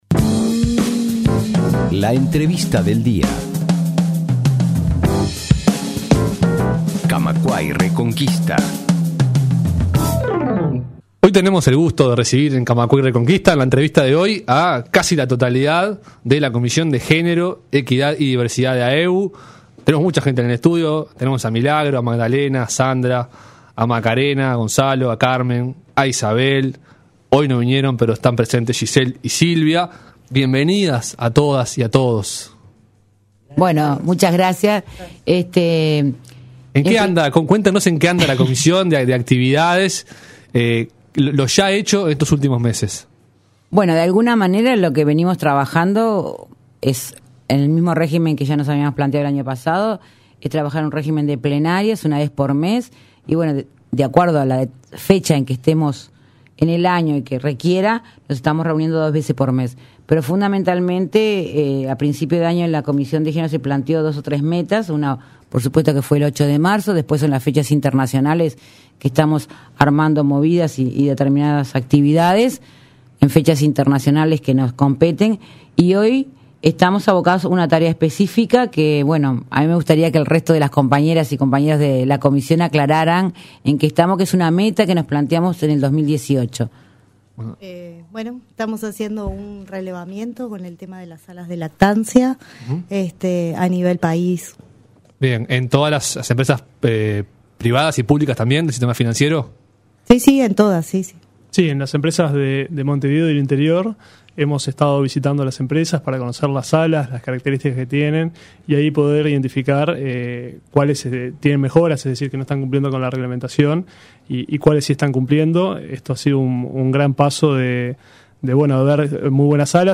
Este lunes integrantes de la Comisión de Género, Equidad y Diversidad de AEBU estuvieron en Camacuá y Reconquista para contar sus últimas novedades, lo hecho recientemente y futuras actividades para este 2018.
En esta entrevista contaron cómo va el relevamiento de la situación actual y qué tipo de aspectos deben mejorar algunas de las salas ya existentes.